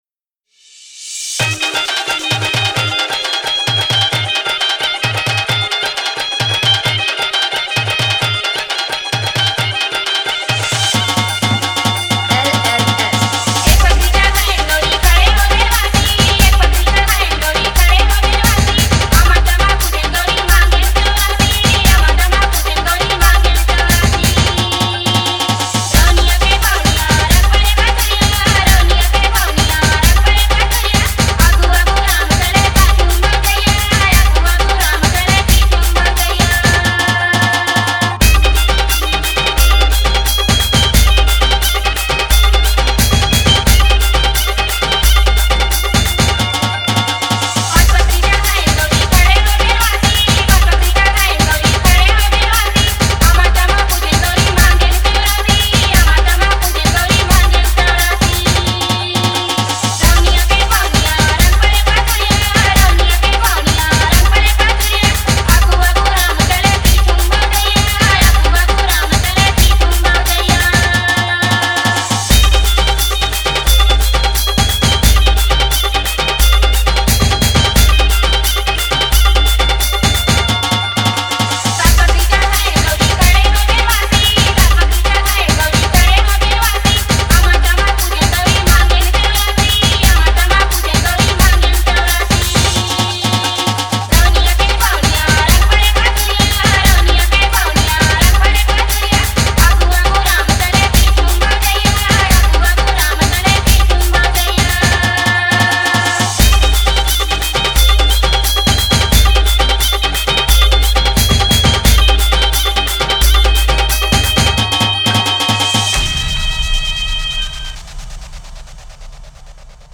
Old Cg Song